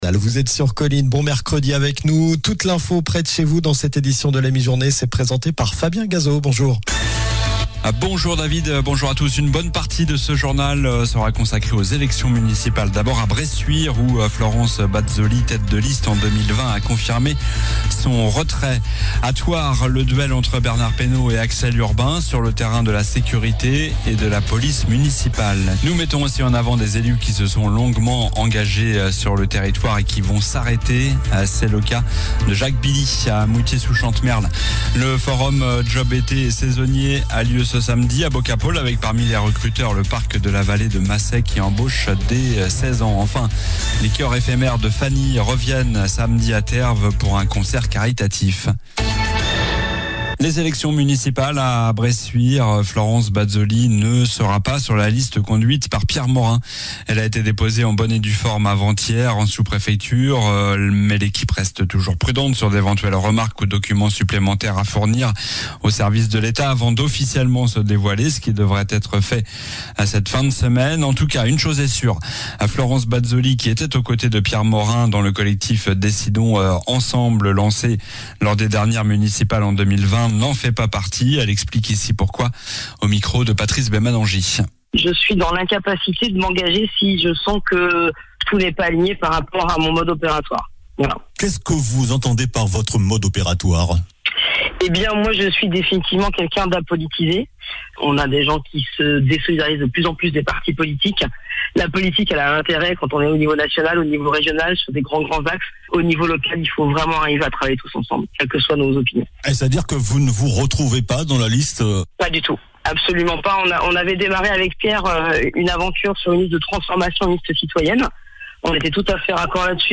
Journal du mercredi 25 février (midi)